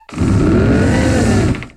Grito de Trevenant.ogg
Grito_de_Trevenant.ogg.mp3